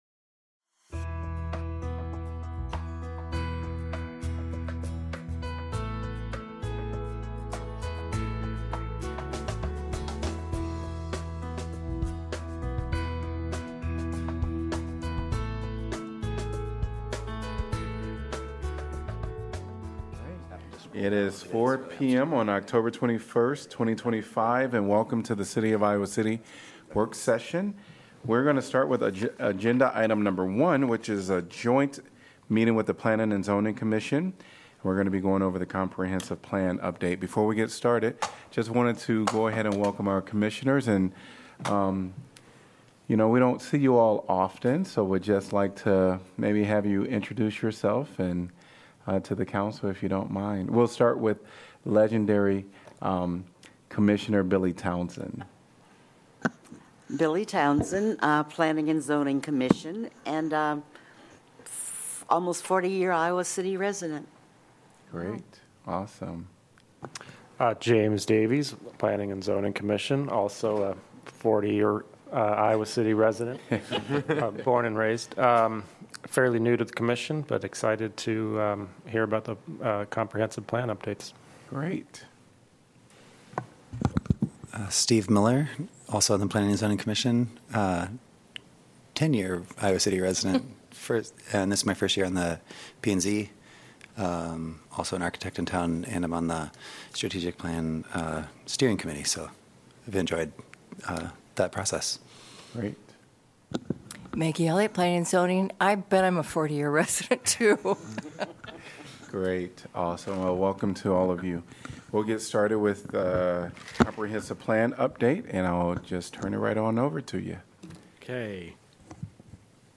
Iowa City City Council Work Session of October 21, 2025
Coverage of the Iowa City Council work session..